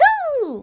toadette_ground_pound_wah.ogg